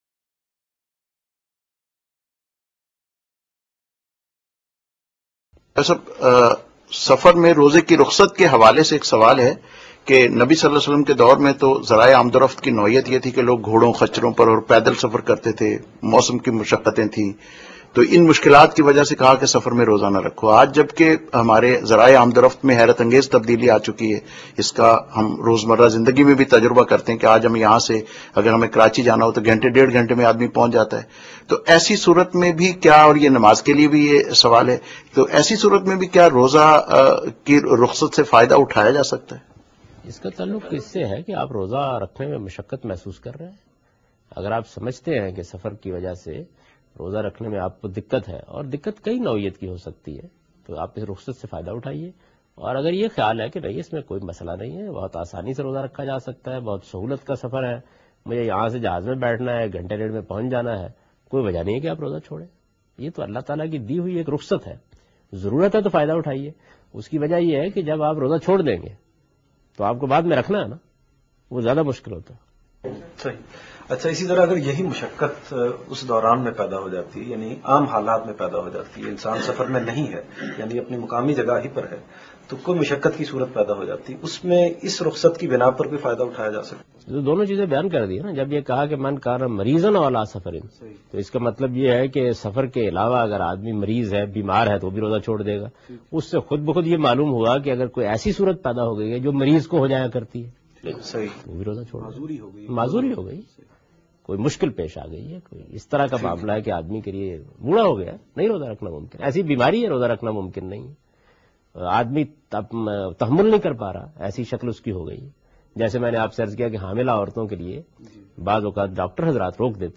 جاوید احمد غامدی رمضان میں بیمار اور مسافر کے لئے آسانی کے متعلق گفتگو کر رہے ہیں